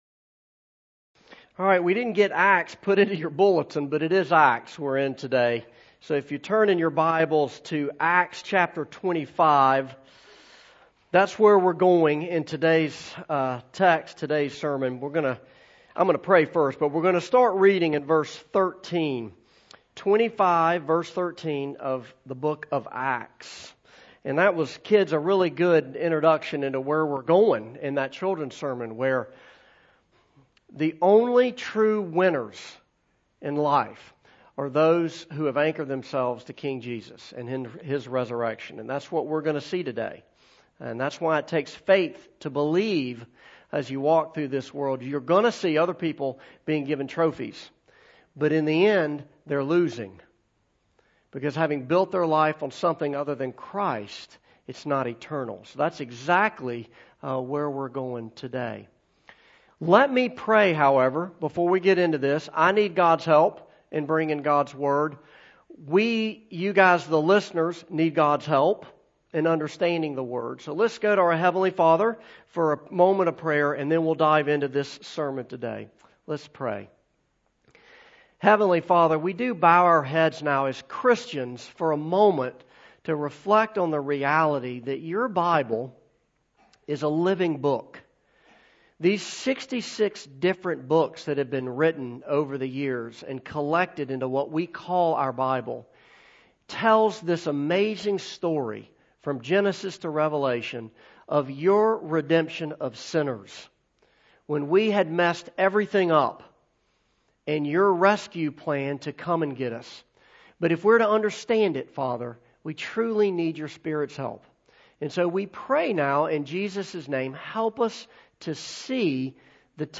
Acts 25:13-26:1 Service Type: Morning Service « Are We in the Last Days?